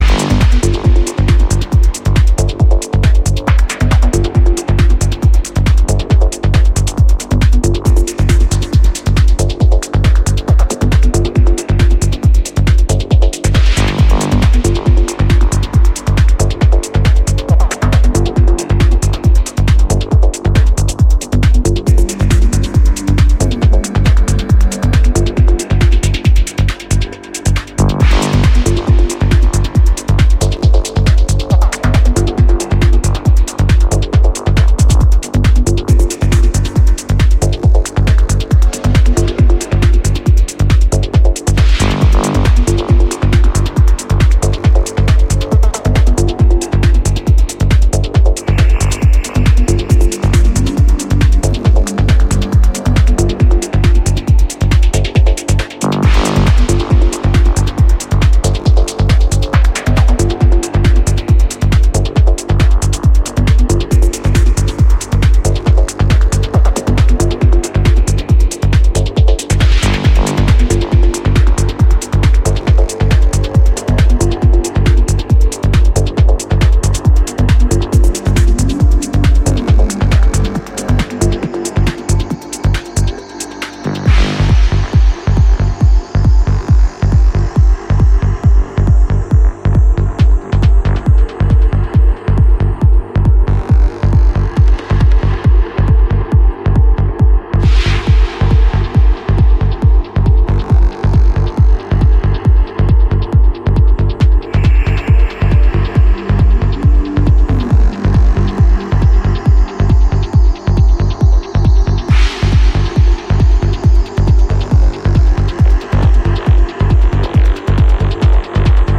ディープにハメてくる感覚が以前よりも研ぎ澄まされてきてると感じさせます。